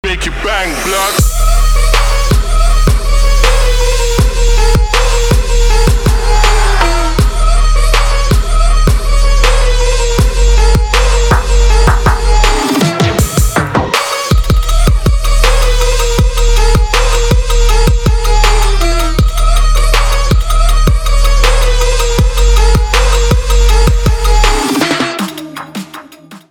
Trap
Bass
Arabian Trap